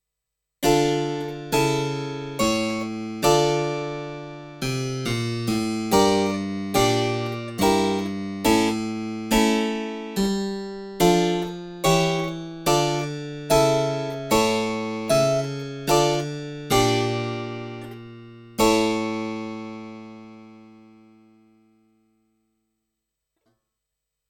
... das ist ja sehr schick daß wir inzwischen mp3 direkt hochladen dürfen, hab ich noch nicht mitgekriegt Anhänge rd2000 cembalo beispiel.mp3 952 KB · Aufrufe: 4.329 rd2000 cembalo tonleiter.mp3 1,1 MB · Aufrufe: 4.288
oberflächlich auf die schnelle gehört mag das ganz nett klingen, aber wenn ich genauer hinhöre klingt das für mich sehr synthetisch.